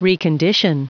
Prononciation du mot : recondition